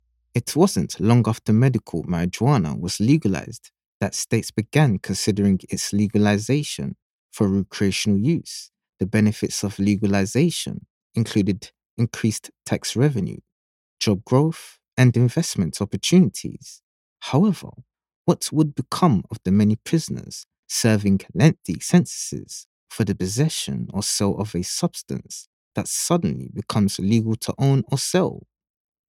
Voice Actors for Training and Instructional Narration
English (Caribbean)
Yng Adult (18-29) | Adult (30-50)